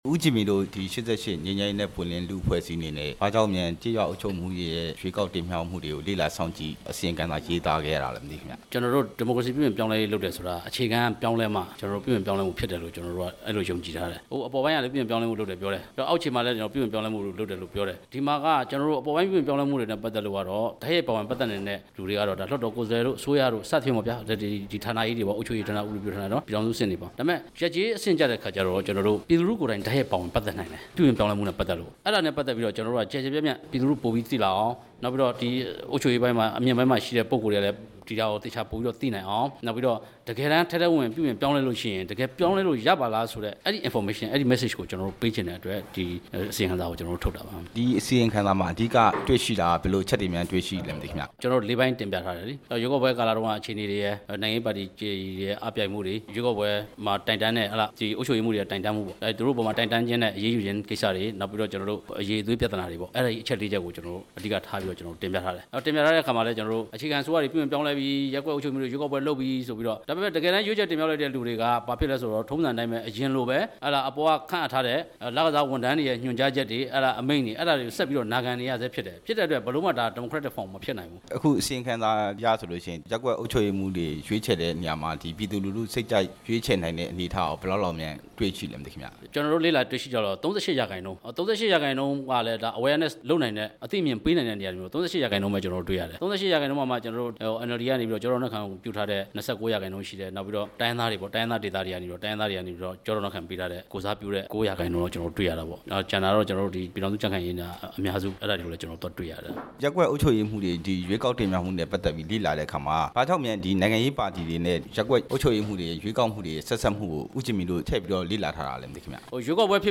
ဒီကနေ့ ရန်ကုန်မြို့ ၈၈ မျိုးဆက် ငြိမ်းချမ်းရေးနဲ့ ပွင့်လင်းလူ့အဖွဲ့အစည်းရုံးခန်းမှာ ကျင်းပခဲ့တဲ့ ရပ်ကွက်၊ ကျေးရွာအုပ်ချုပ်ရေးမှူးများ ပြုပြင်ပြောင်း လဲရေးဆိုင်ရာ အစီရင်ခံစာ သတင်းစာရှင်းလင်းပွဲမှာ ၈၈ မျိုးဆက် လူထုဆက်ဆံရေးဋ္ဌာနတာဝန်ခံ ကိုဂျင်မီက အခုလိုပြောခဲ့တာပါ။